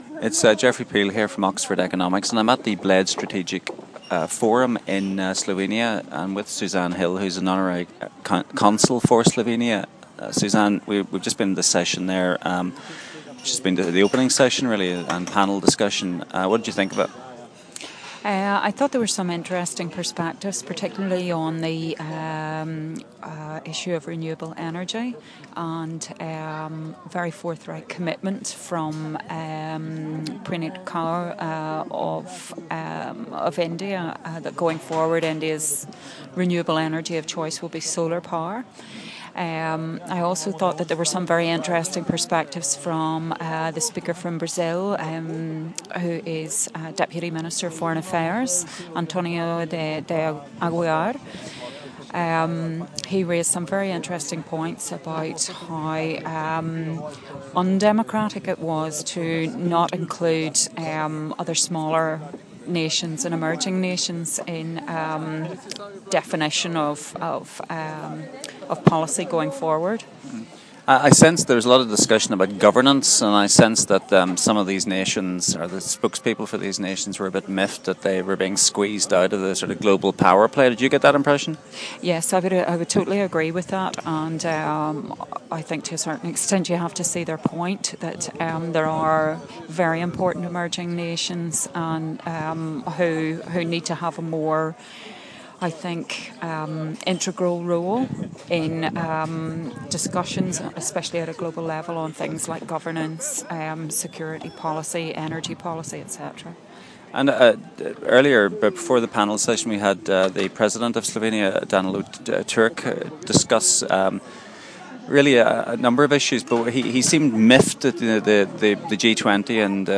Bled Strategic Forum - Interview